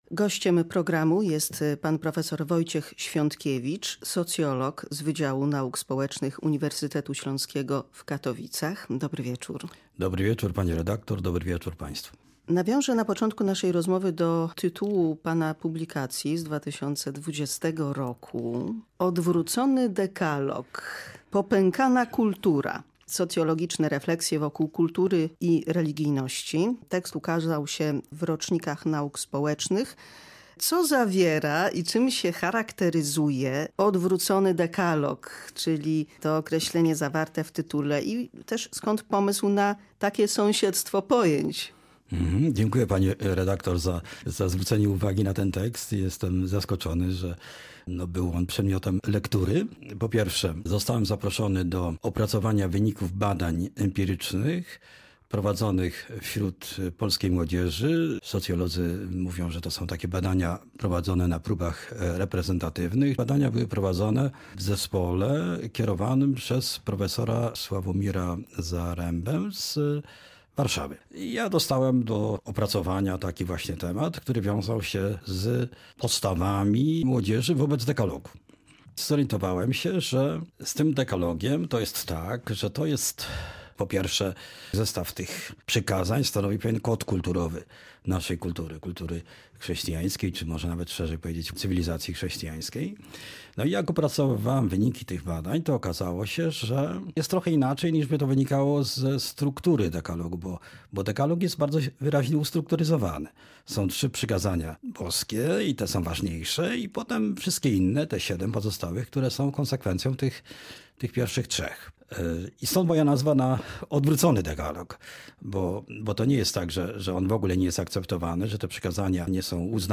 w studiu Polskiego Radia Rzeszów